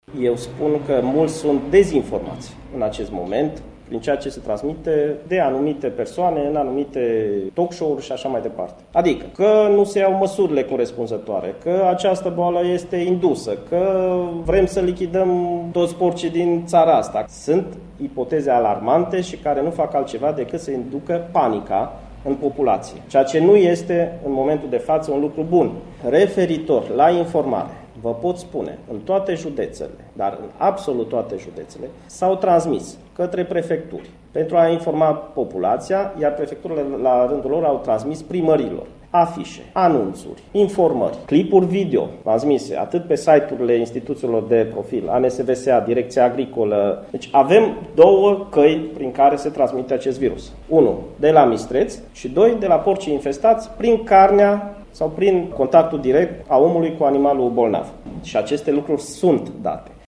Informarea cetăţenilor români în privinţa pestei porcine africane s-a făcut corespunzător – susţine secretarul de stat în Ministerul Agriculturii, Sorin Roşu Mareş. Într-o conferinţă de presă susţinută la Bistriţa, acesta a declarat că o mare parte dintre oameni sunt dezinformaţi în emisiuni televizate, unde se emit ipoteze alarmante: